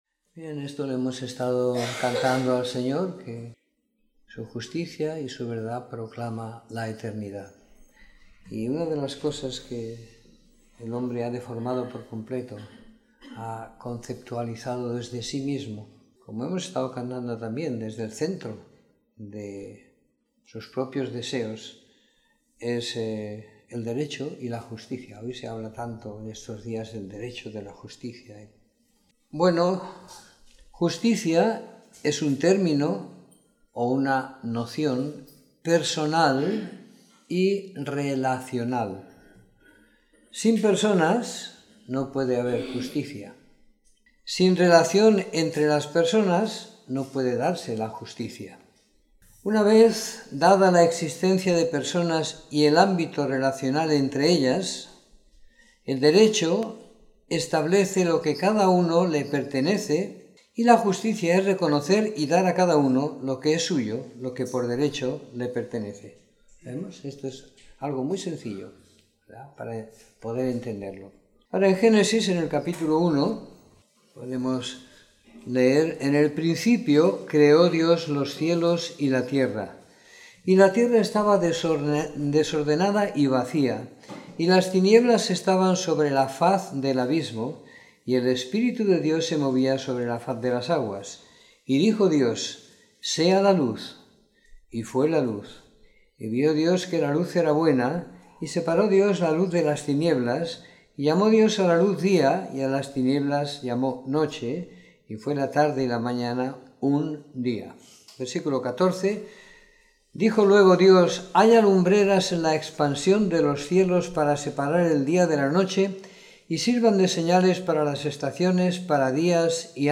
Domingo por la Mañana . 08 de Octubre de 2017